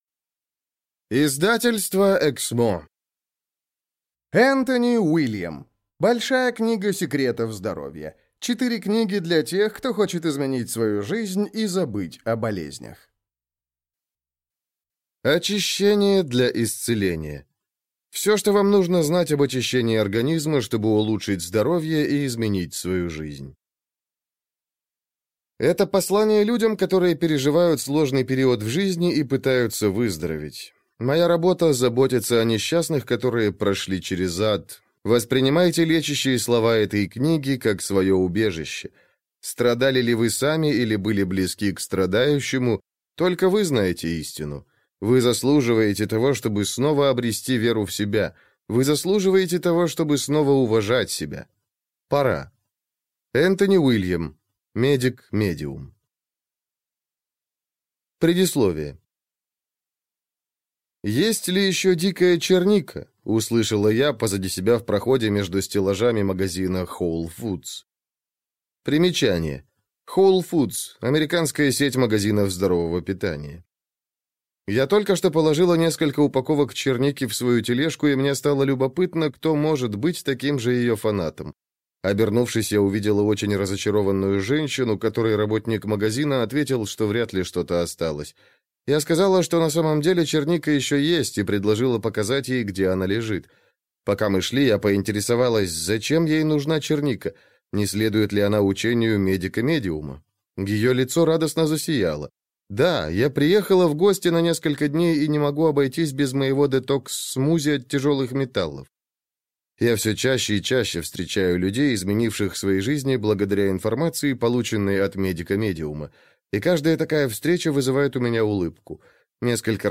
Большая книга секретов здоровья. 4 книги для тех, кто хочет изменить свою жизнь и забыть о болезнях (слушать аудиокнигу бесплатно) - автор Энтони Уильям